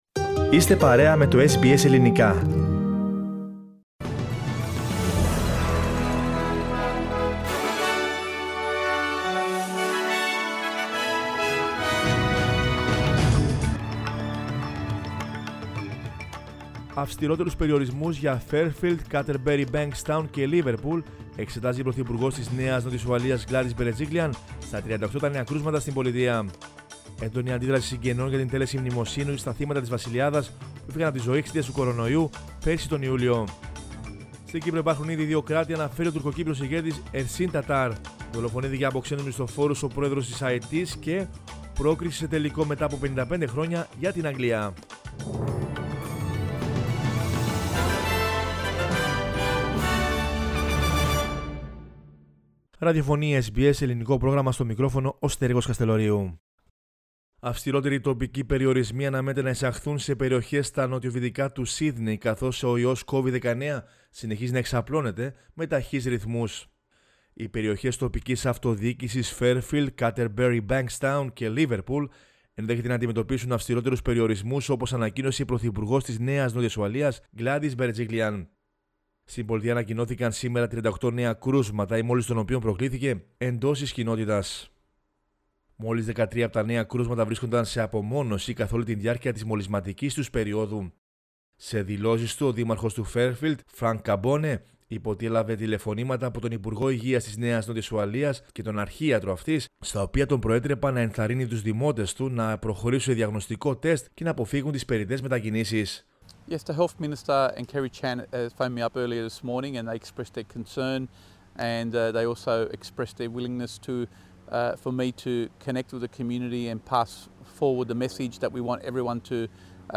News in Greek from Australia, Greece, Cyprus and the world is the news bulletin of Thursday 8 July 2021.